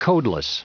Prononciation du mot codeless en anglais (fichier audio)
Prononciation du mot : codeless